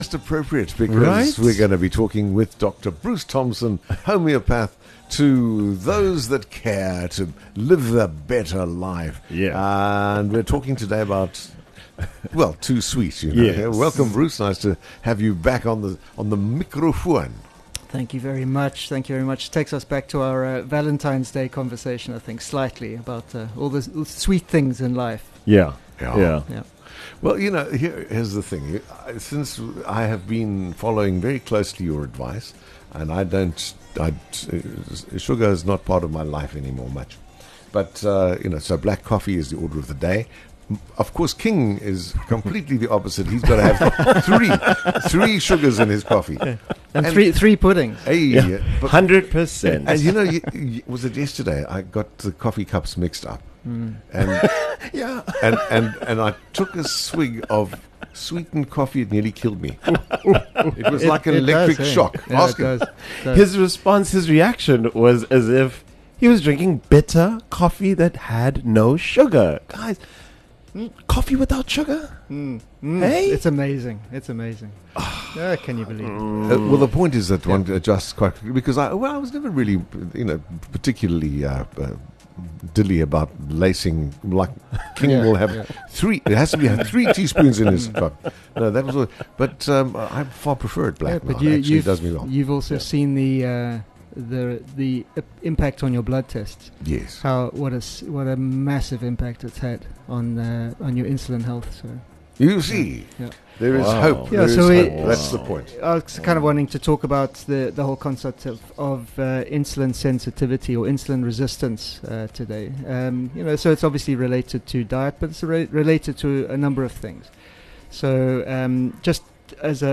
Join us as we interview